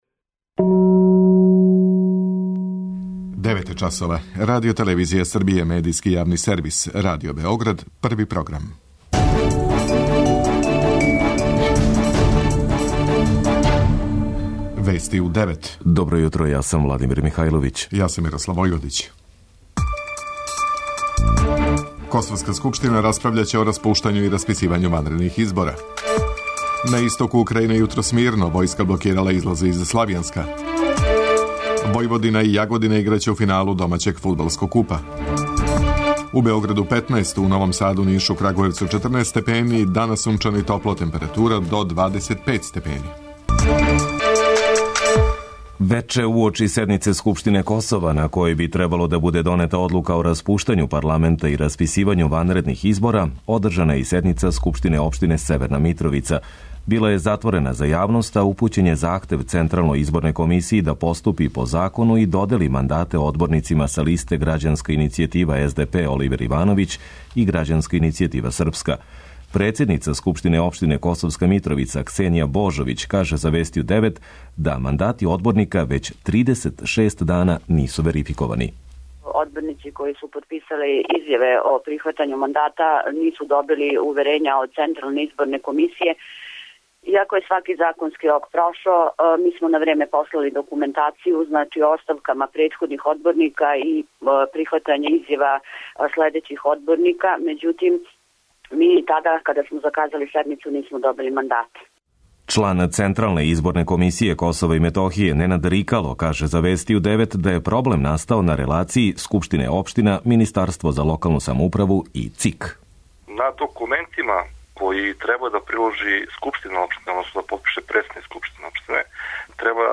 Вести у 9 | Радио Београд 1 | РТС